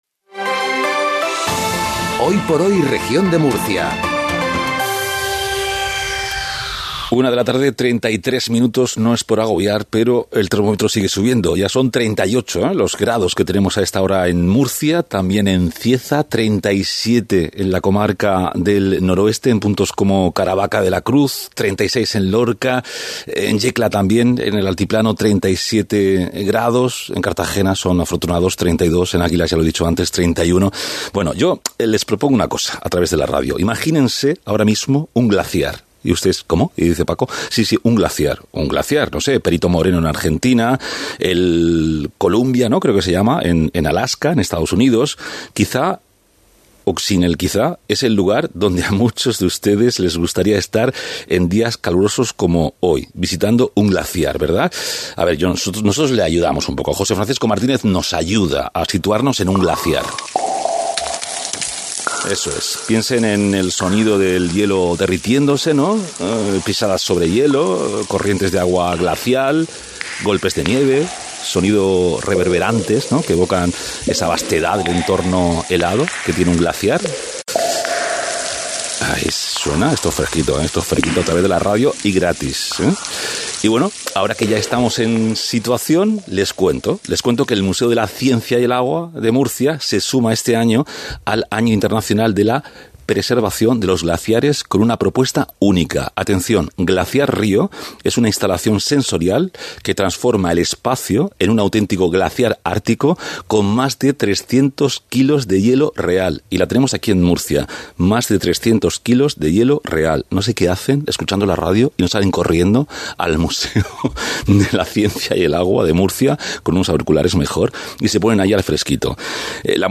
Actividades verano: Diego Avilés, concejal de Cultura e Identidad en el Ayuntamiento de Murcia